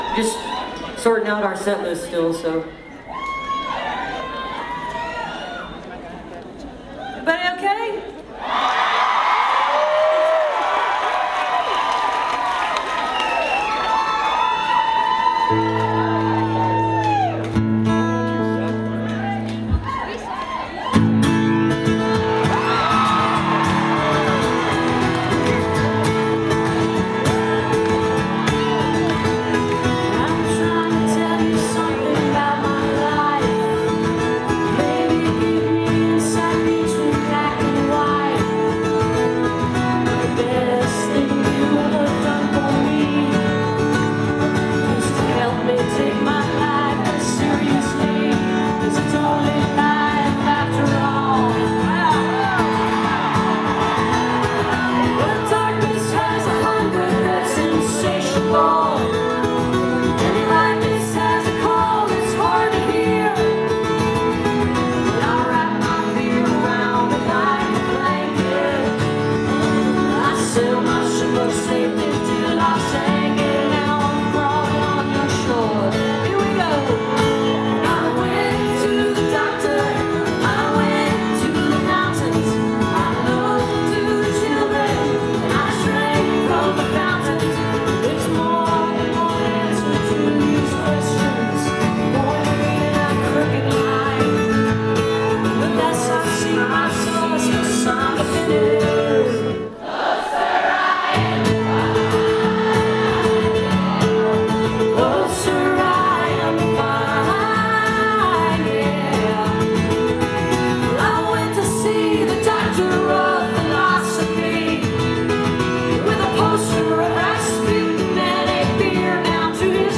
(acoustic duo)